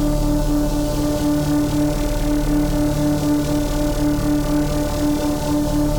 Index of /musicradar/dystopian-drone-samples/Non Tempo Loops
DD_LoopDrone1-D.wav